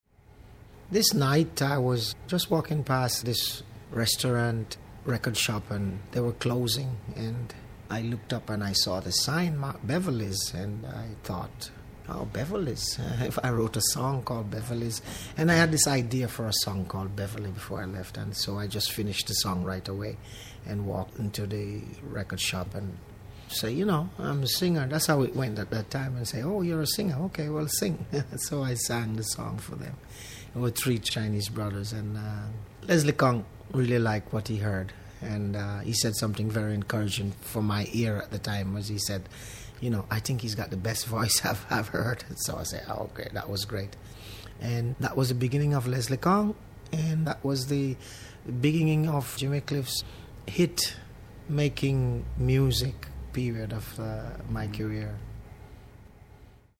As it happened, Jimmy had started writing a ska  tune called Dearest Beverley which he decided to finish so he could present it to Leslie Kong. I’ll let Jimmy relate the story: